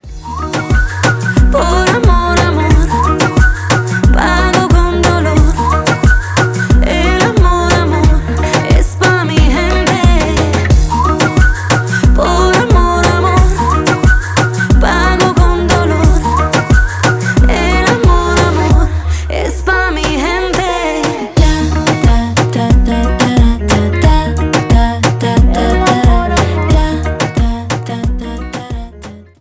Поп Музыка # клубные # латинские